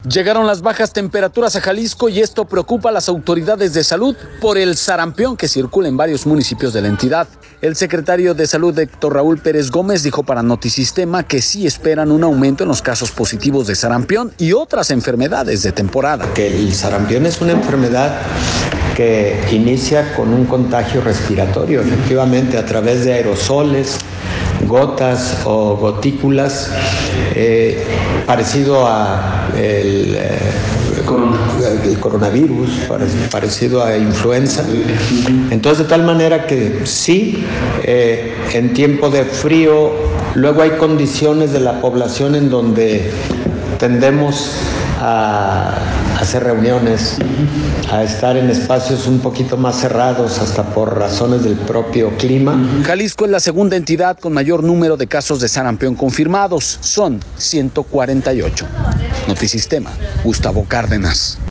Llegaron las bajas temperaturas a Jalisco, y esto preocupa a las autoridades de salud por el sarampión que circula en varios municipios de la entidad. El secretario de Salud, Héctor Raúl Pérez Gómez, dijo para Notisistema que sí esperan un aumento en los casos positivos de sarampión y otras enfermedades de temporada.